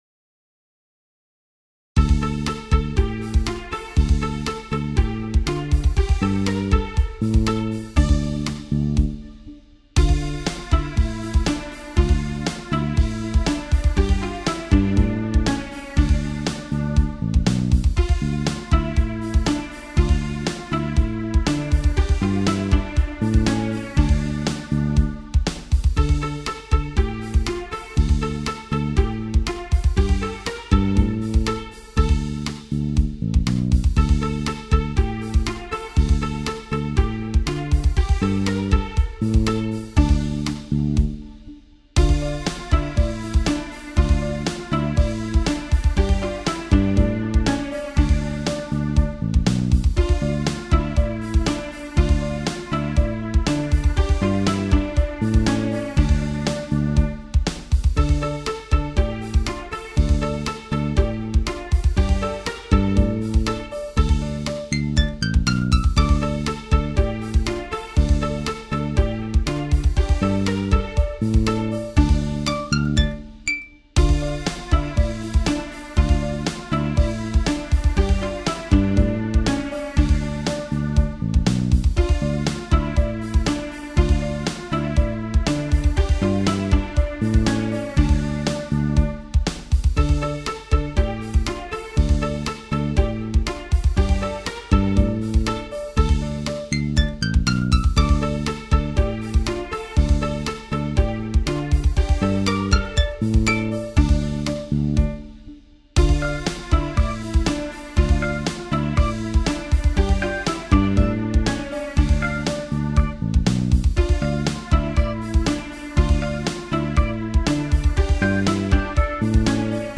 Vekselsang mellom Forsanger/Liturg og forsamling